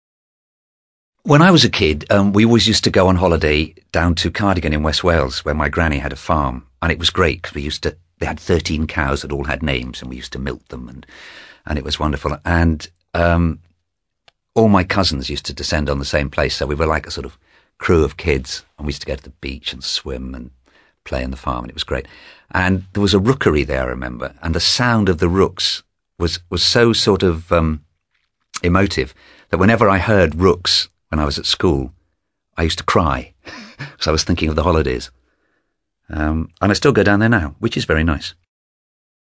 ACTIVITY 165: You will listen to two men talking about childhood holidays.
SPEAKER 2